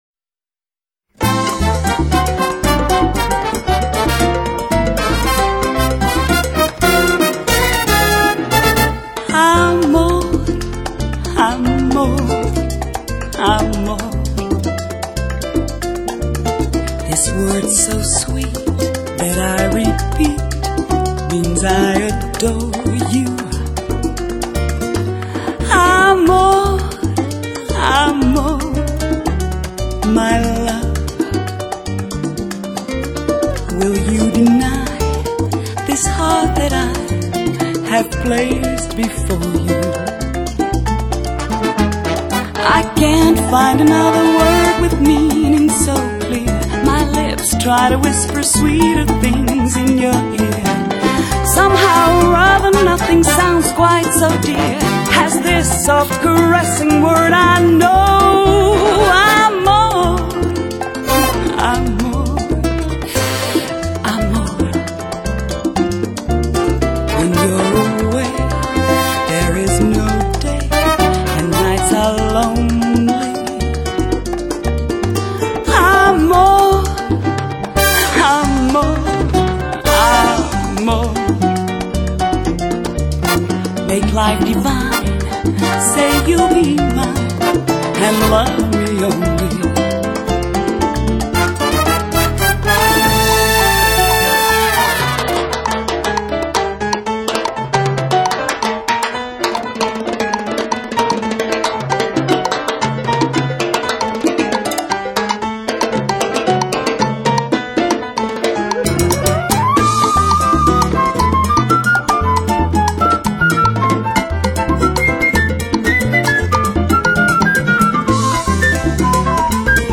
拉丁风情的曼妙JAZZ……
火热而纯正的伦巴、恰恰、莎莎、波列罗节奏